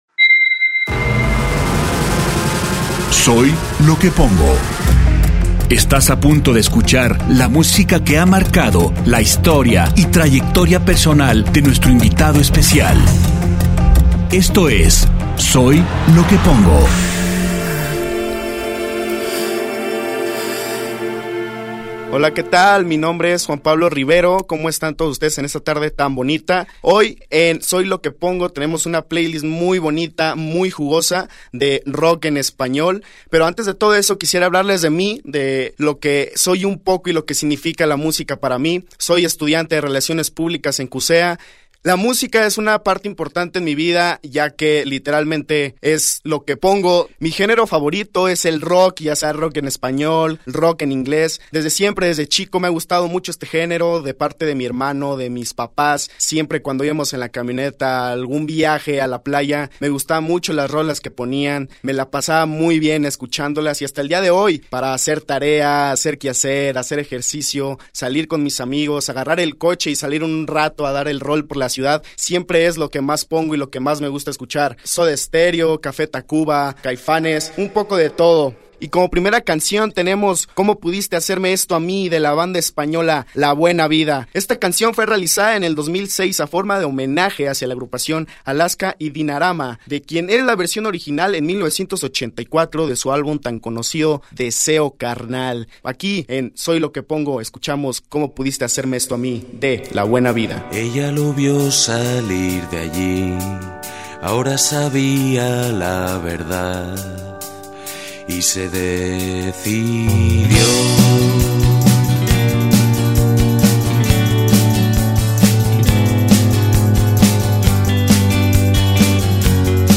Un programa que representa la influencia musical del melómano invitado. Con formato de PlayList e intervención del melómano en turno, buscando el aporte de amplio espectro estilístico musical.